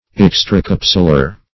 Search Result for " extracapsular" : The Collaborative International Dictionary of English v.0.48: Extracapsular \Ex`tra*cap"su*lar\, a. (Anat.) Situated outside of a capsule, esp. outside the capsular ligament of a joint.